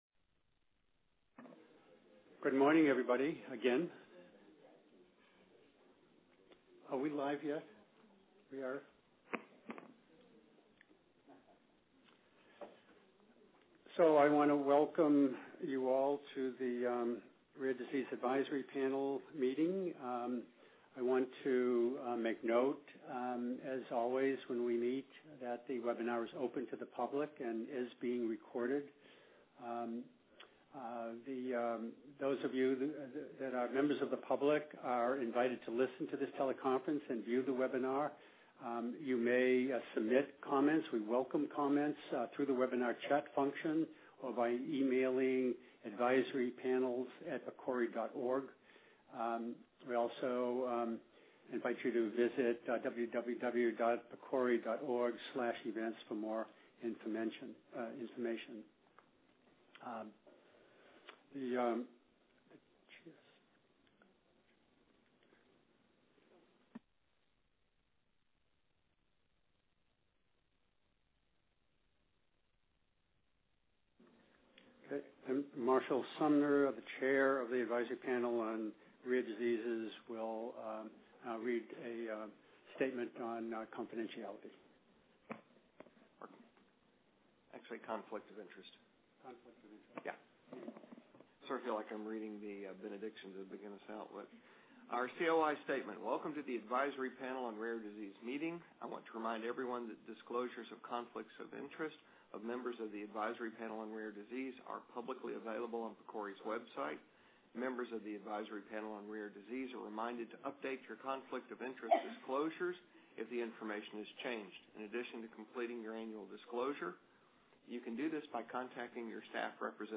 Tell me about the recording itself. Listen to the Teleconference Audio Recording